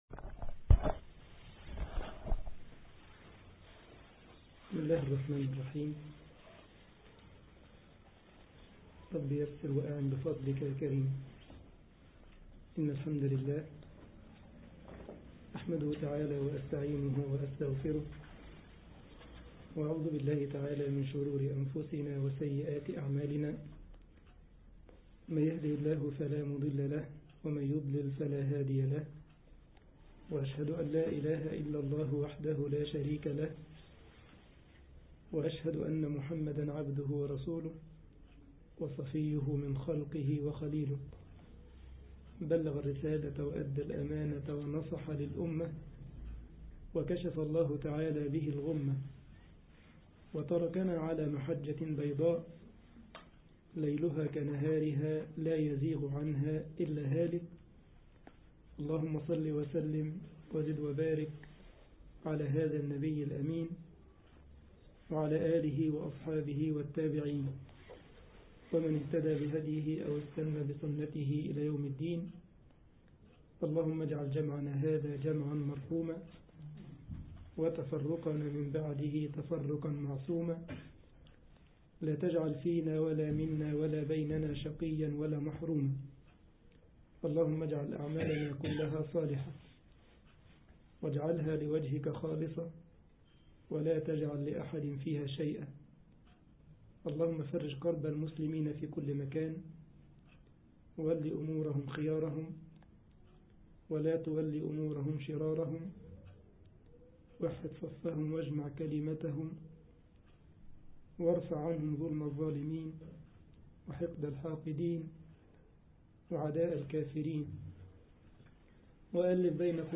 مصلى جامعة السارلند ـ ألمانيا